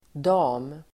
Uttal: [da:m]